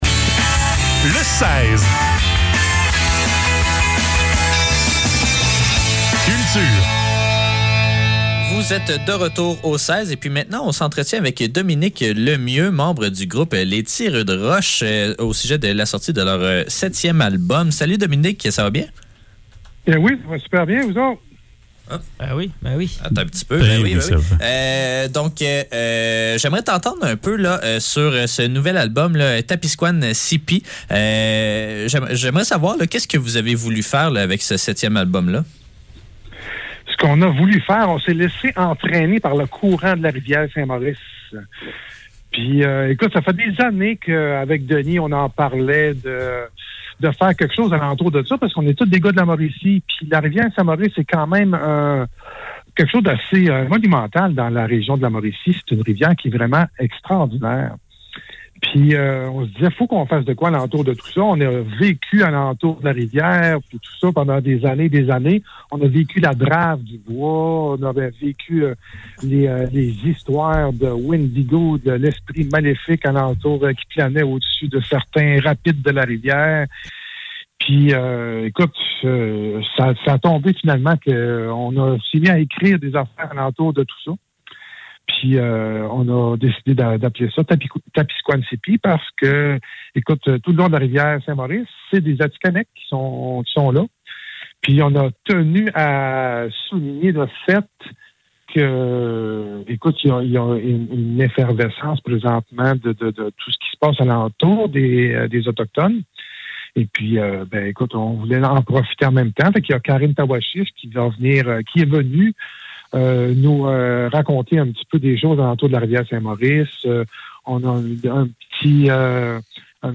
Le seize - Entrevue avec Les tireux d'roches - 30 novembre 2021
Entrevue-avec-Les-Tireux-d-roches.mp3